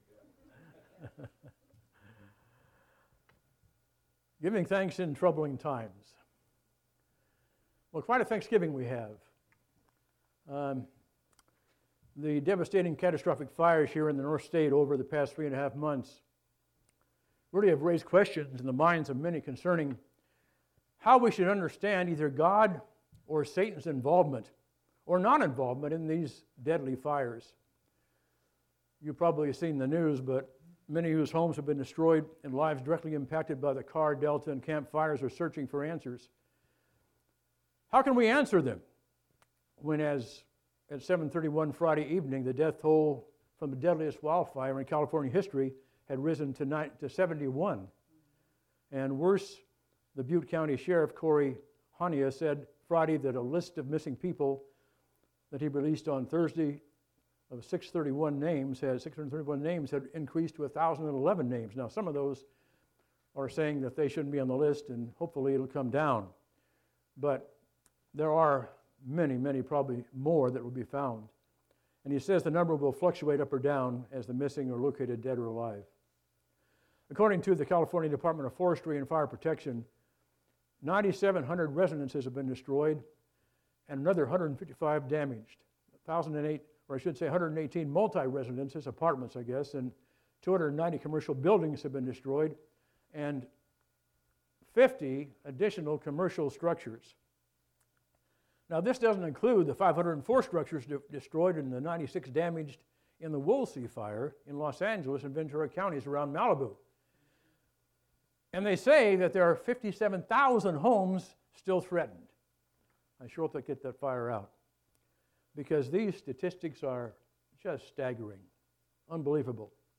Series: Special Sermon